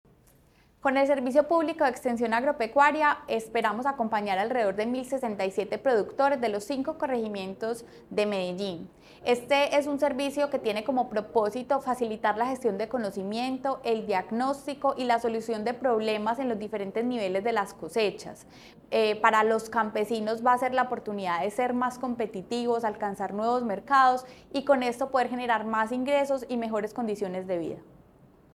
Palabras de María Fernanda Galeano Rojo, secretaria de Desarrollo Económico